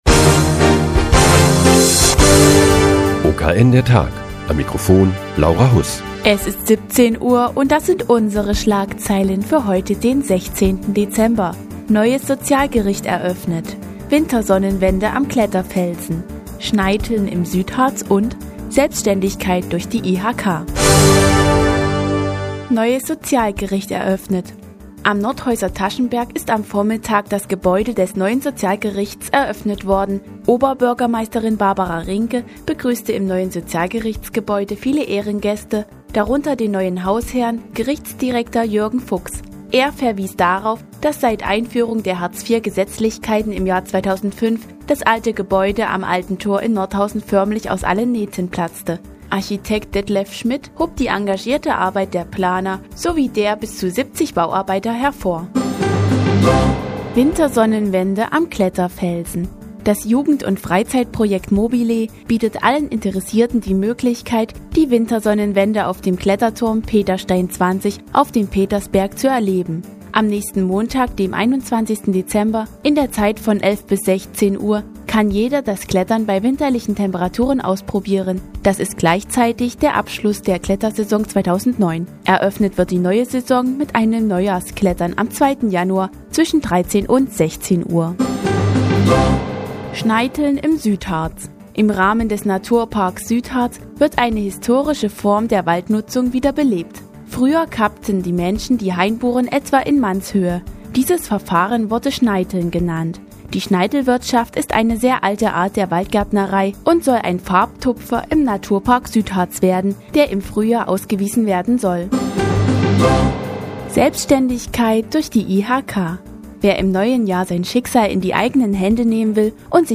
Die tägliche Nachrichtensendung des OKN ist nun auch in der nnz zu hören. Heute geht es um die Eröffnung des neuen Sozialgerichts und ein Existenzgründerseminar der IHK.